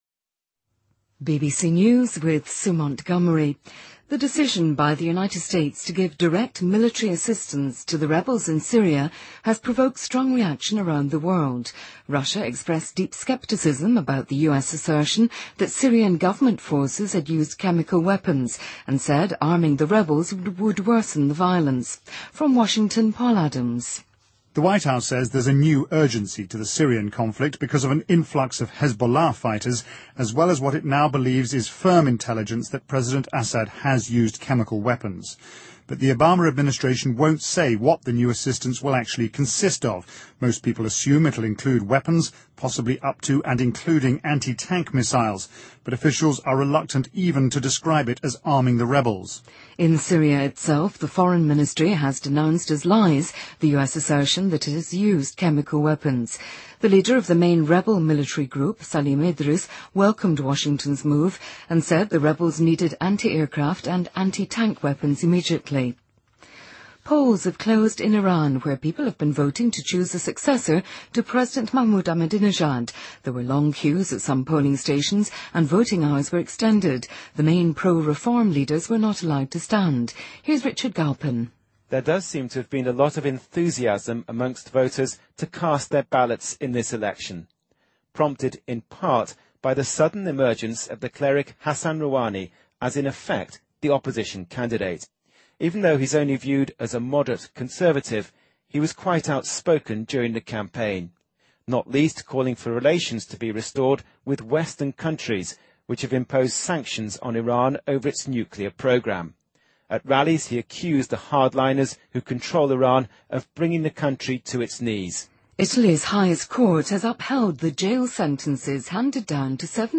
BBC news,2013-06-15